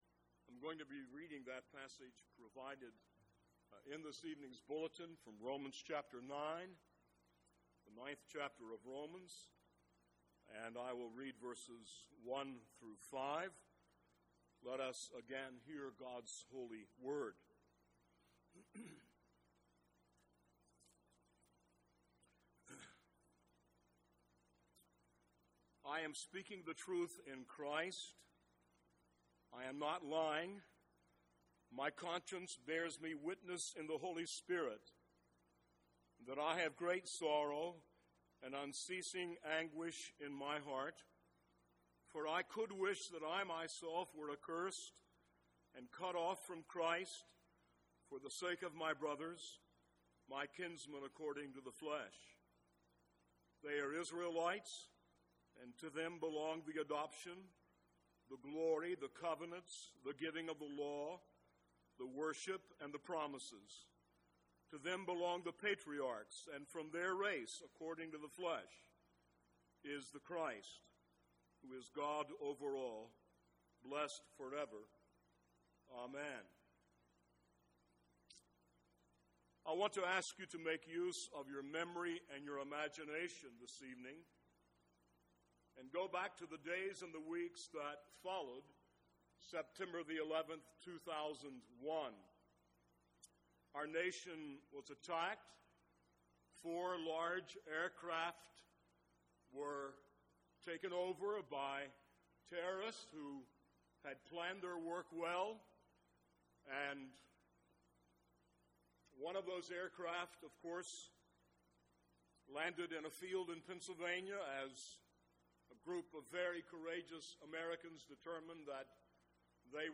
Romans 9-11 (Low volume)